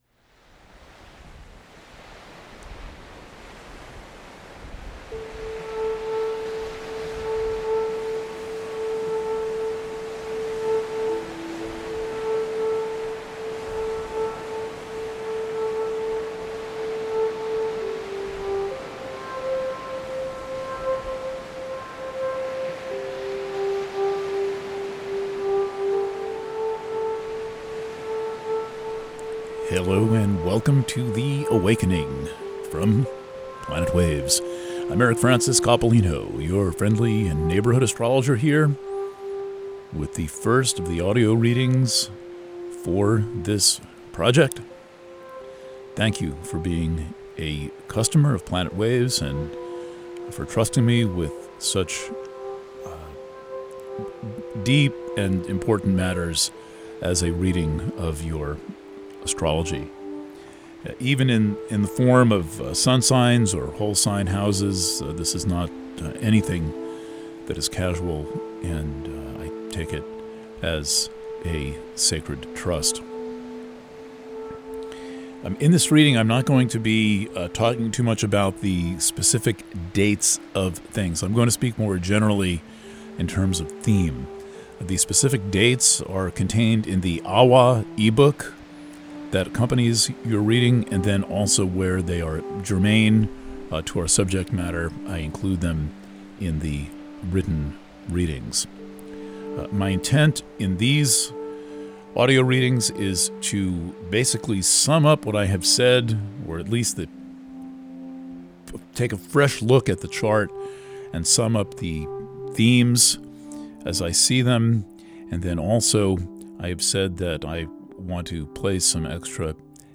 Preview – The Awakening for Aries Purchasing options for The Awakening Preview – Written reading Preview – The Awakening for Aries – PDF Preview – Audio reading Alternate Player (Audio Only) Views: 154